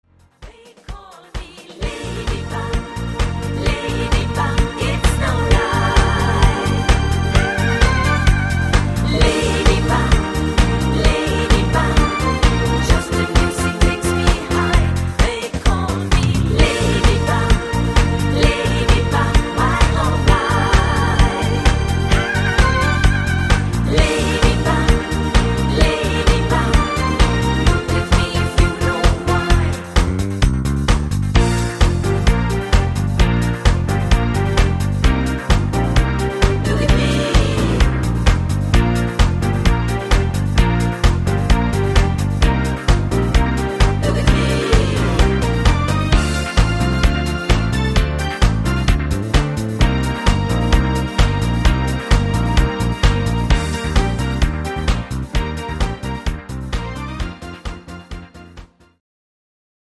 Bb Dur